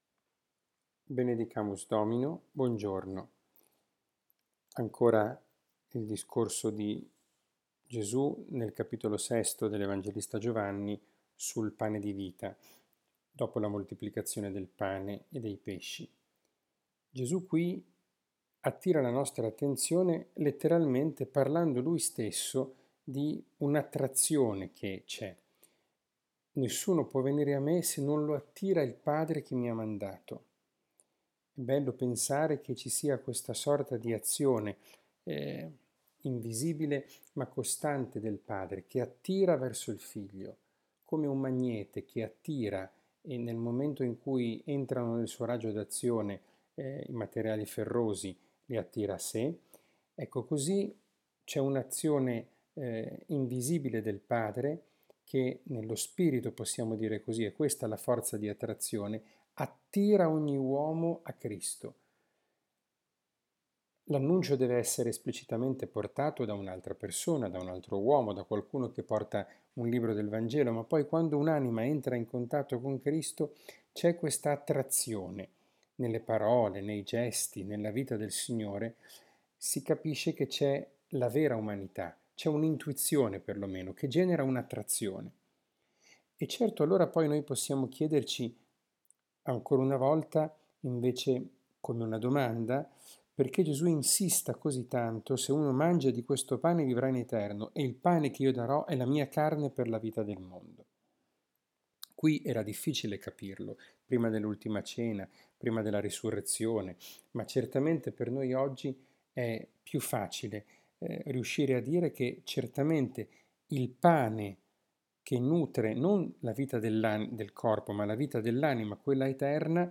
catechesi, Parola di Dio, podcast